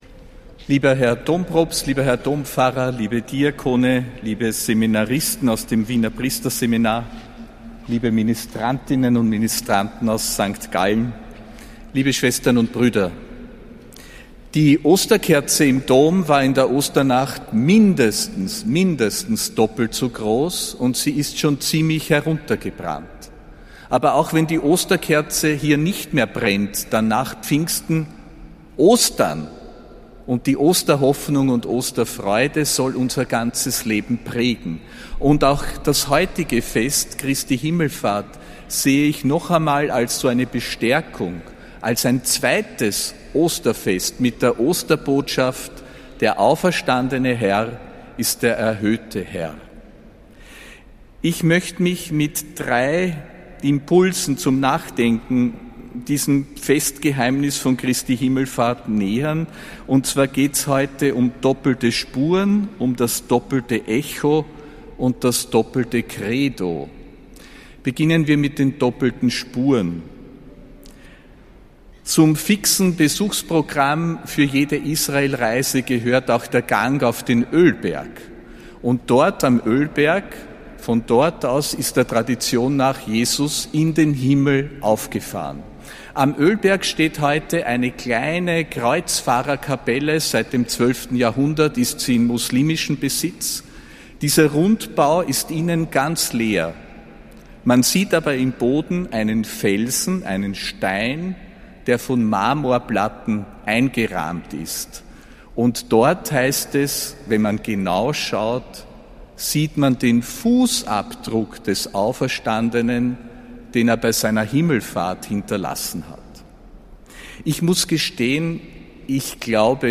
Predigt von Josef Grünwidl zu Christi Himmelfahrt (29. Mai 2025)
Predigt des Apostolischen Administrators Josef Grünwidl zu Christi